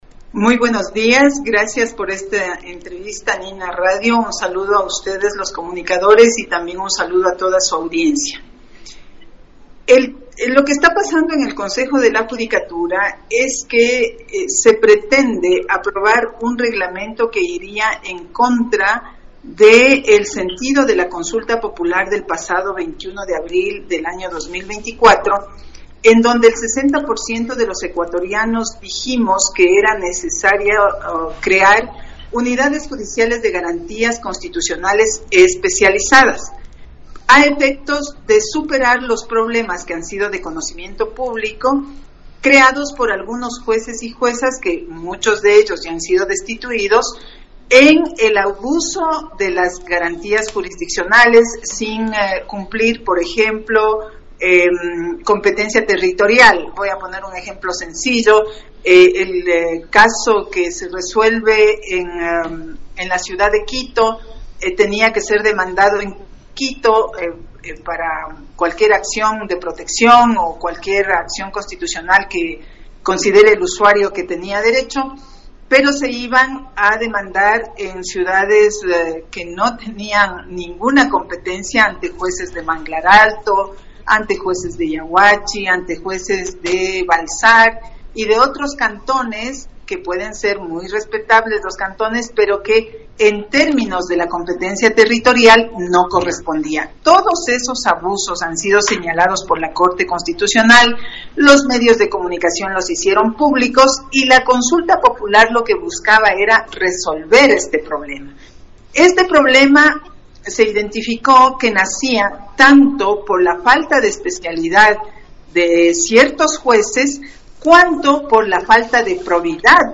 Dra. Solanda Goyes, vocal del Consejo de la Judicatura.
Solando Goyes una de las vocales, en entrevista en Nina radio el 24 de abril de 2025, dio a conocer que el CJ va de mal a peor, por la mala administración de Mario Godoy, a quien considera tener una actitud dictatorial, al no dialogar con las dos vocales, no receptar sugerencias y propuestas que ayudarían a la institución.